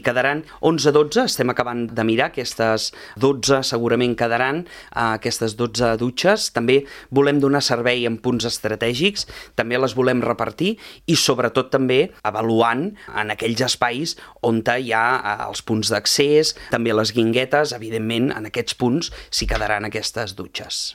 En aquest últim cas, Grima ha avançat a l’entrevista política de Ràdio Calella TV que la setmana vinent s’enllestiran els treballs al paviment i el carrer es podrà reobrir de nou, de dalt abaix.